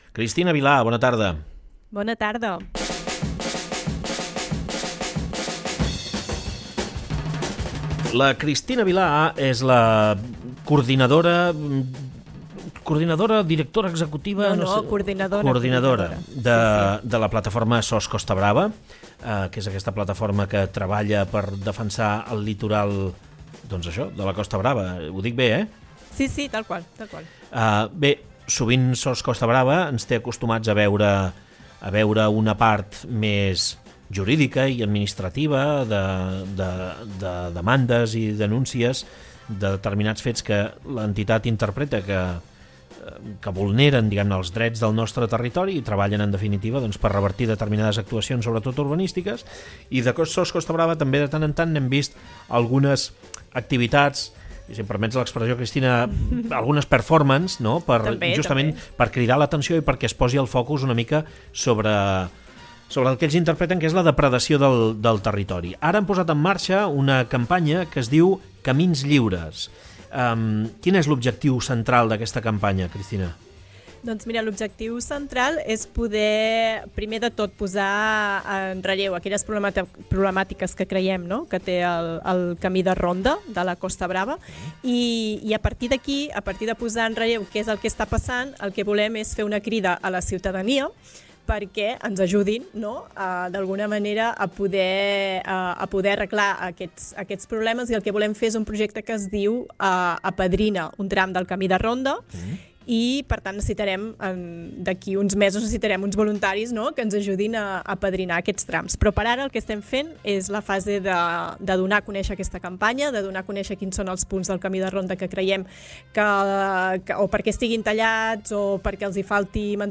En una entrevista a De cap a cap